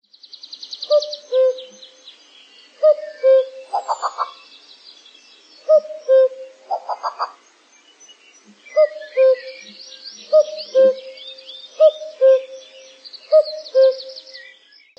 kagu.ogg